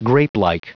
Prononciation du mot grapelike en anglais (fichier audio)
Prononciation du mot : grapelike